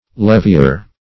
levier - definition of levier - synonyms, pronunciation, spelling from Free Dictionary Search Result for " levier" : The Collaborative International Dictionary of English v.0.48: Levier \Lev"i*er\ (l[e^]v"[i^]*[~e]r), n. One who levies.